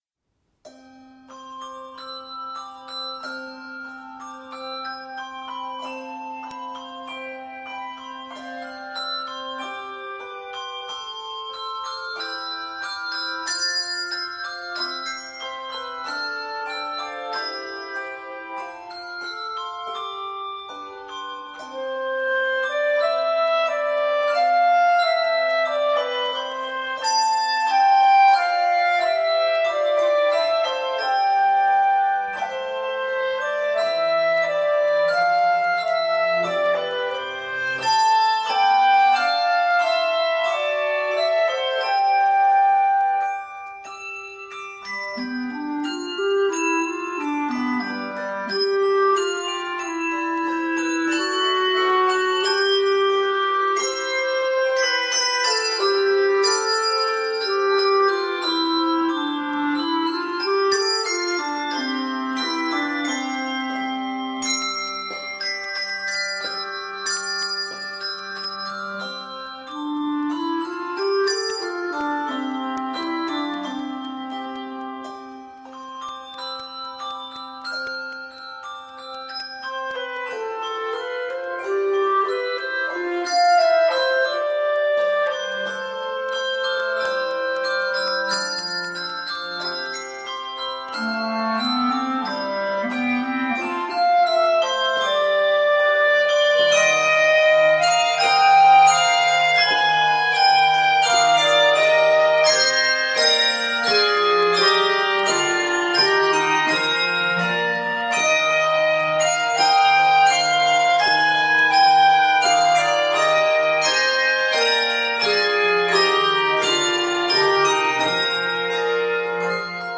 is a solid, majestic arrangement